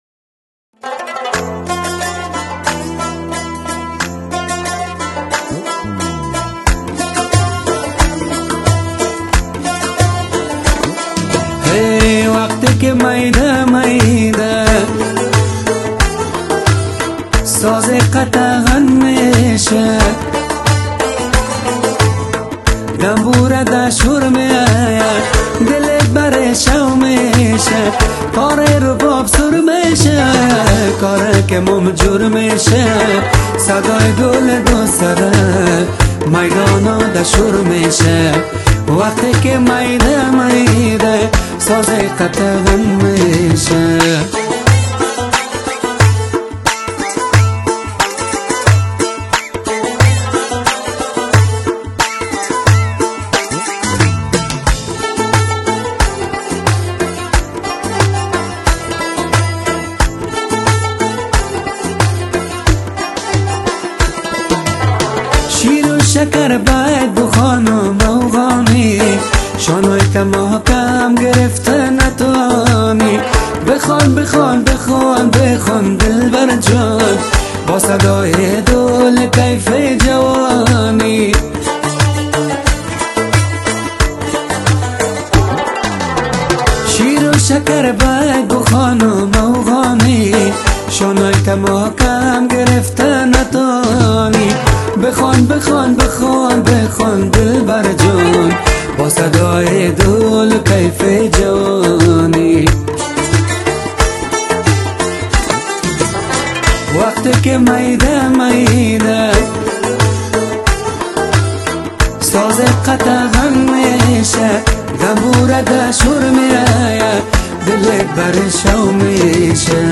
آهنگ افغانی